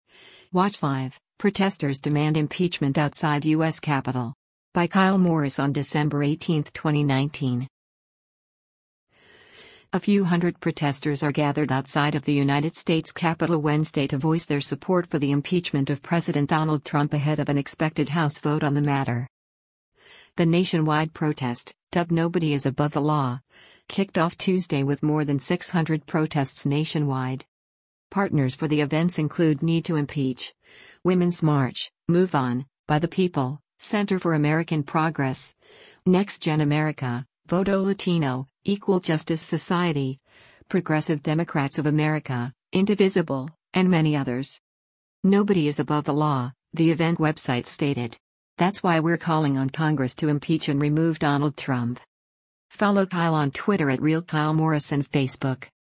A few hundred protesters are gathered outside of the United States Capitol Wednesday to voice their support for the impeachment of President Donald Trump ahead of an expected House vote on the matter.